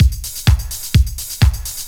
BC Beat 2_127.wav